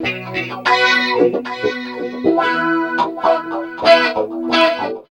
69 GTR 2  -L.wav